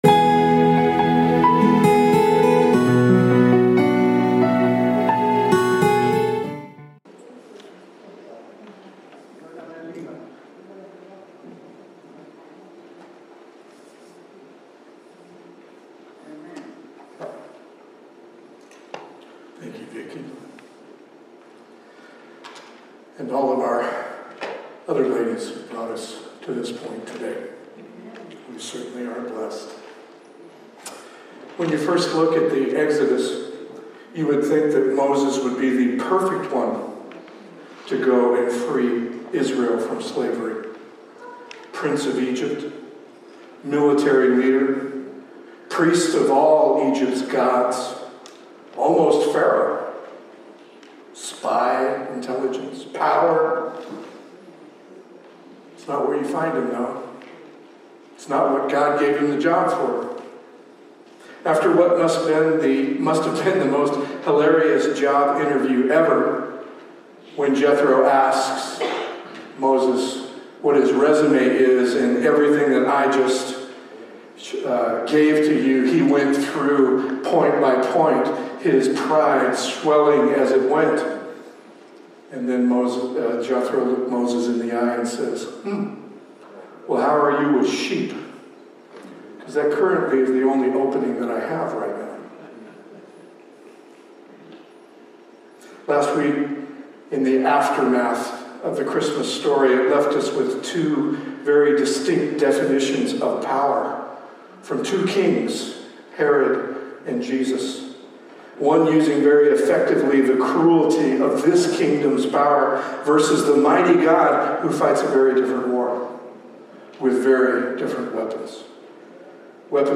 Audio Sermon
Apology for the audio quality decrease – Just an input error we will have corrected this coming week!